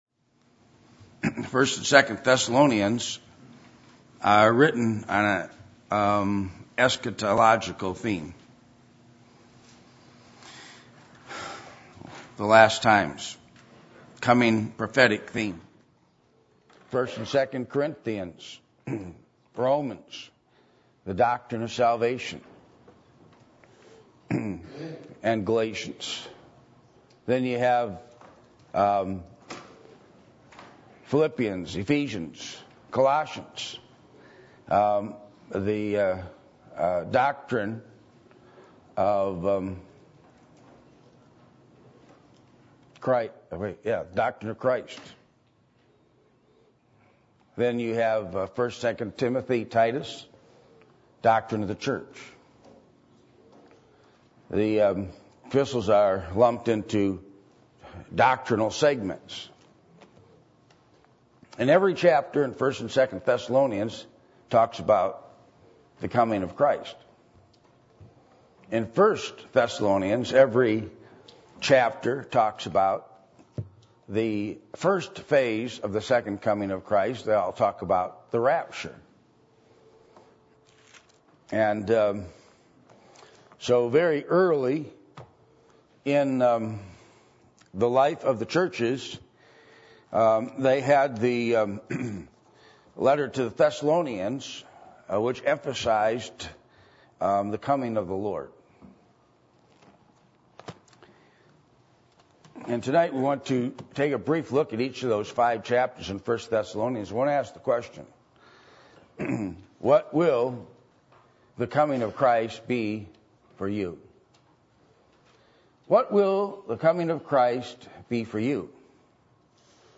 Passage: 1 Thessalonians 1:1-10 Service Type: Sunday Evening %todo_render% « What Kind Of Person Are You?